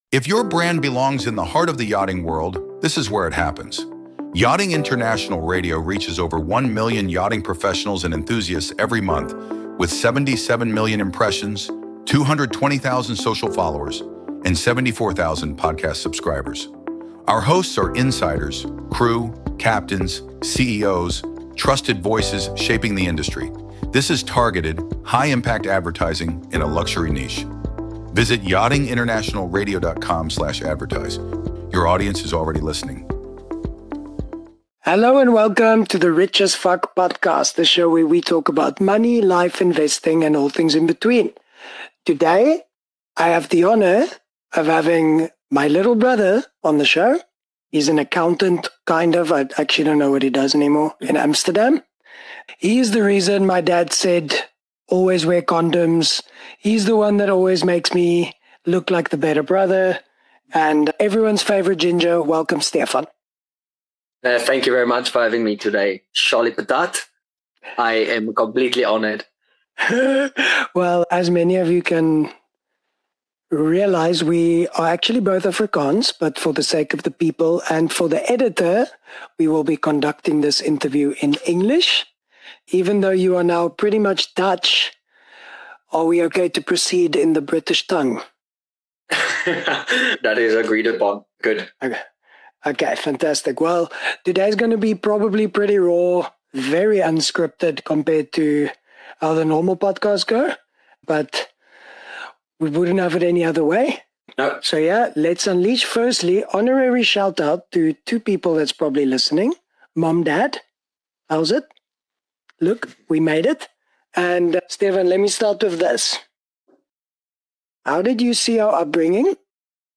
💸 Two brothers.
What starts as sibling banter quickly turns into a candid discussion about upbringing, financial habits, career paths, and how money quietly shapes decisions around lifestyle, relationships, and long-term freedom. There is humour, self-awareness, a fair amount of swearing, and no pretending that anyone has this fully figured out.